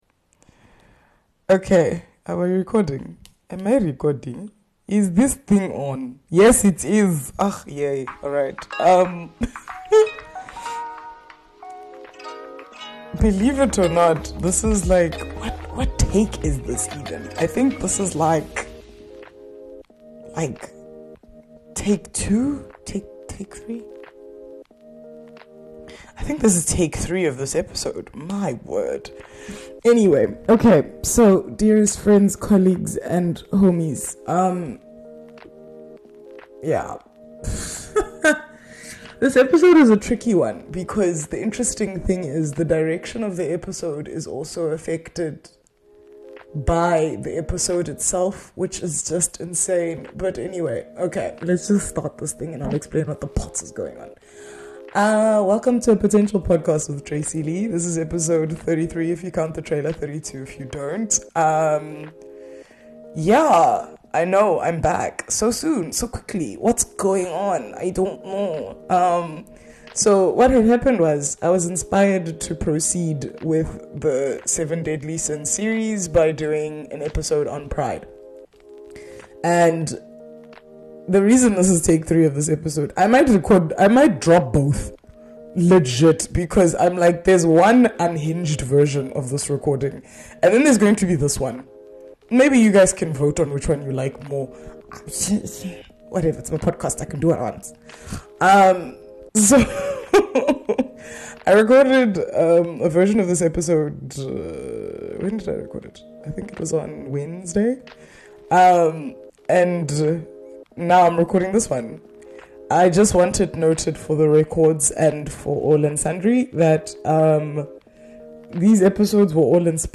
My journey of learning how to grow a great podcast while actually doing it. Podcast reviews, Interviews and vibes.